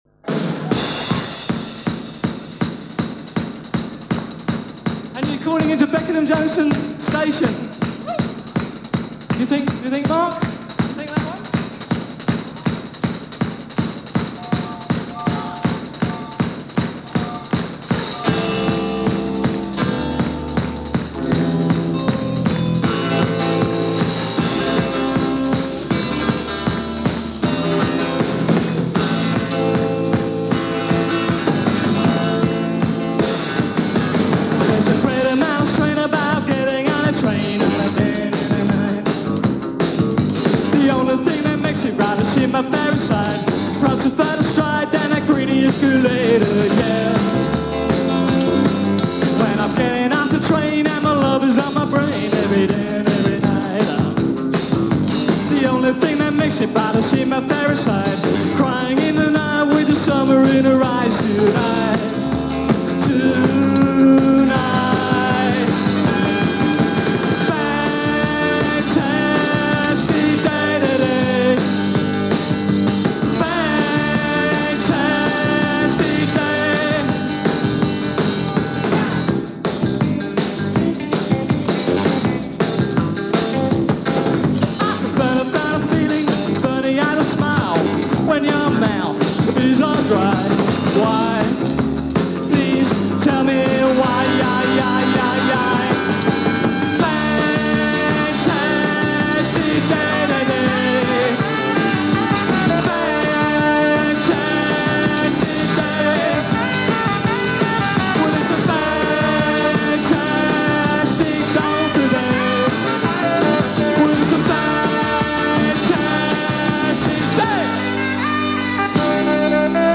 Recorded in Munich March 1, 1983
When Funk got comfy with New Wave